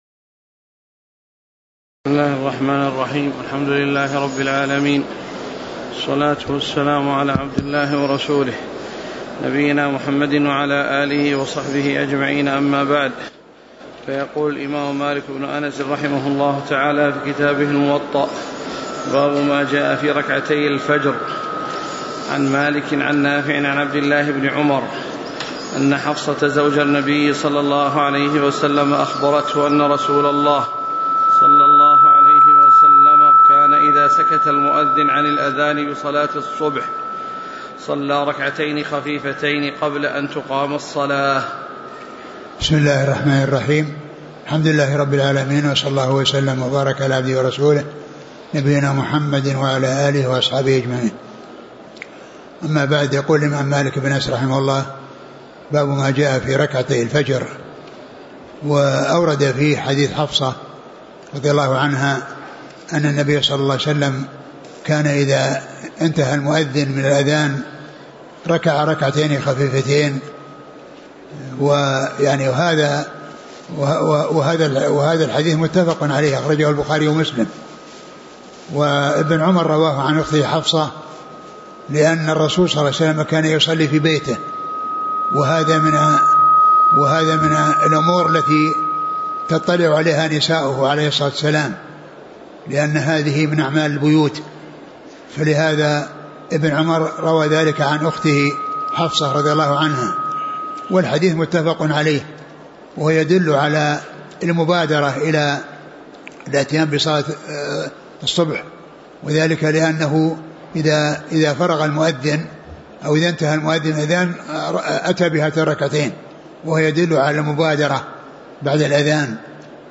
شرح كتاب الموطأ 💫 لفضيلة الشيخ عبد المحسن العبّاد
الدرس : الثالث والعشرون